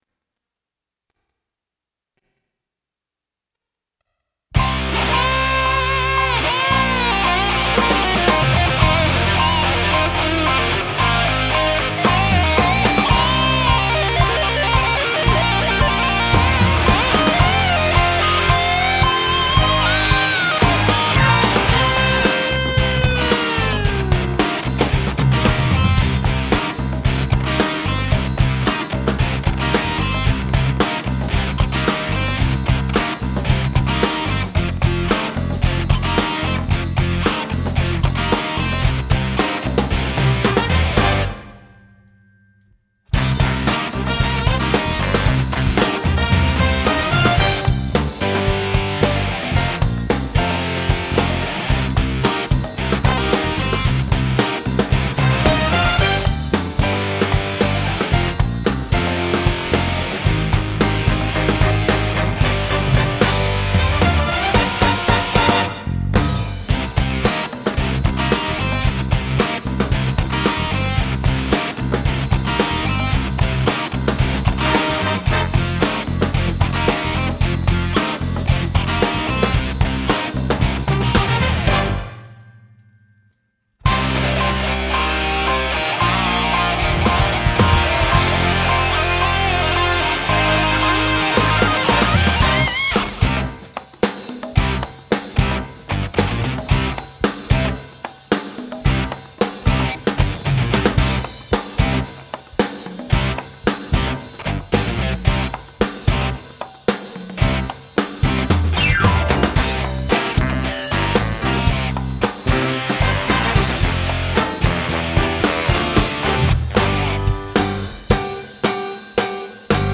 Without singing: